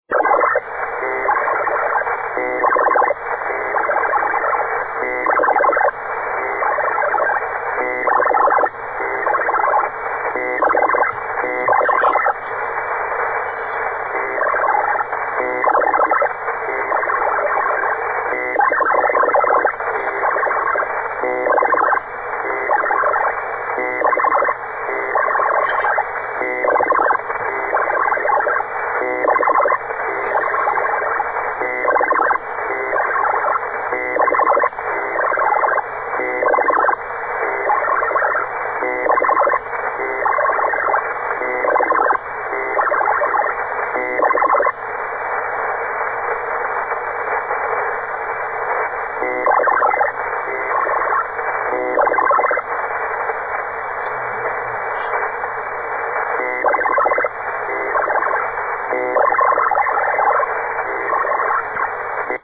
Noise Source:  Unidentified
Where Found:  HF Bands